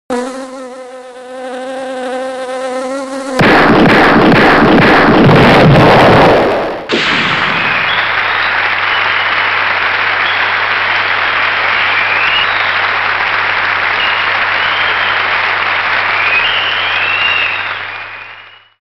(Demo Studio/Midi - 2004)